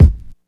2 Kick -seatbelts.wav